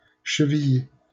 Chevilly (French pronunciation: [ʃəviji]